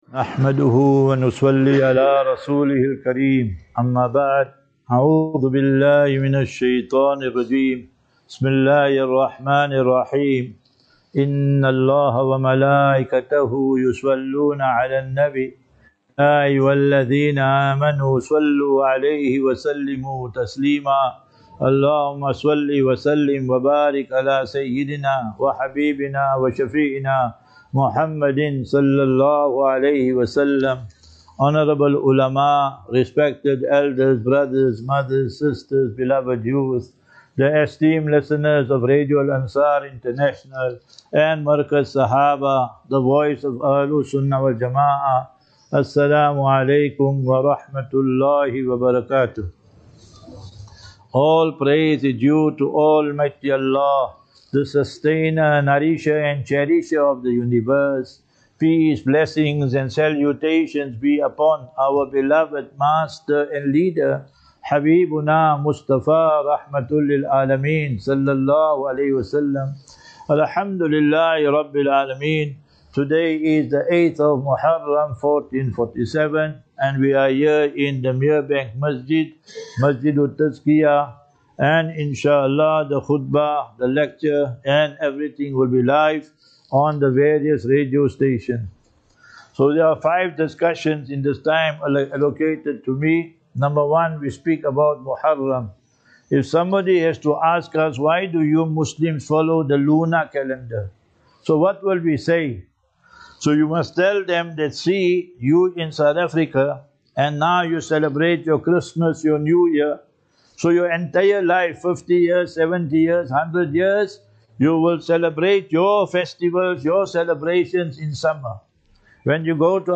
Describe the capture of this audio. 4 Jul 04 July 25 - Jumu,ah Lecture at Refinery Masjid Merebank (KZN).